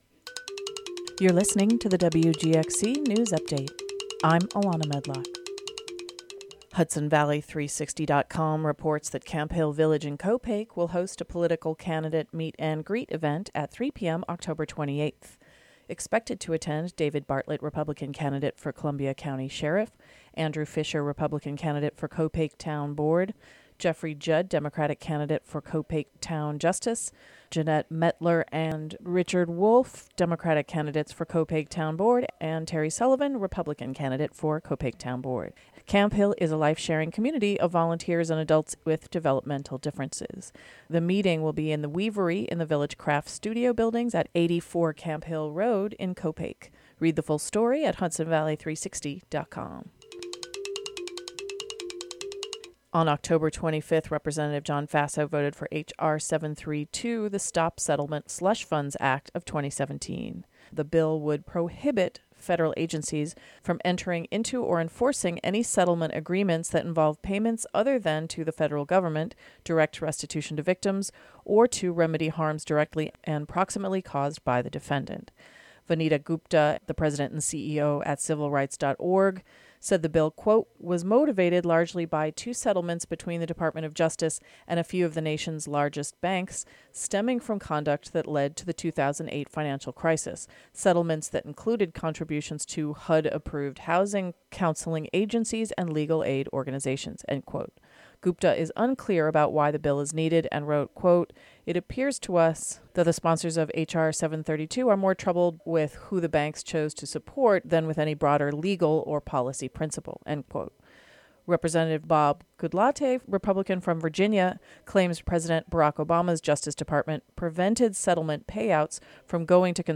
The U.S. House Energy and Commerce committee held an oversight hearing for the Federal Communications Commission Oct. 25. Representatives asked the commissioners about President Donald Trump's threats to pull licences of broadcasters he disagrees with, among other issues. Click here to hear a report about the hearing.